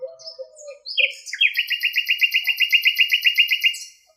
• bird singing - amsel - blackbird 1.wav
Recorded with a Tascam DR 40 in a small village garden, listening to different types of birds, singing alongside and flapping wings.
bird_singing_-_amsel_-_blackbird_1_z9i.wav